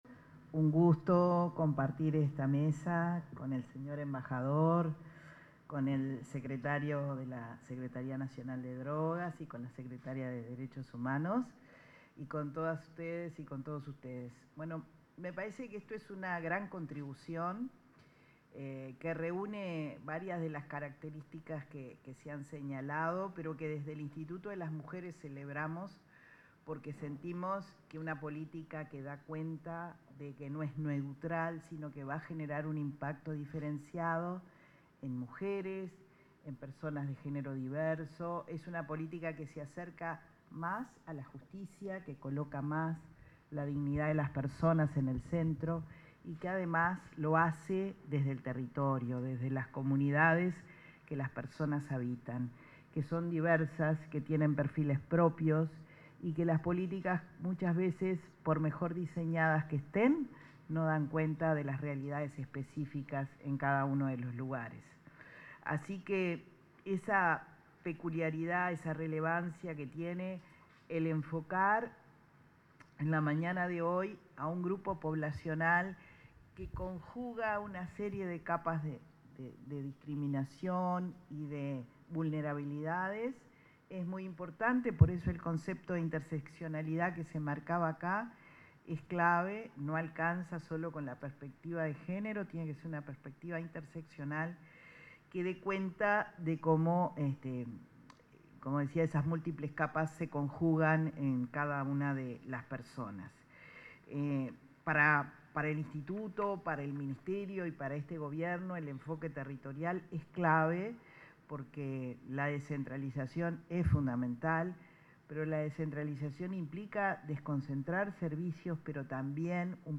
Palabras de autoridades en presentación de guía de la Secretaría Nacional de Drogas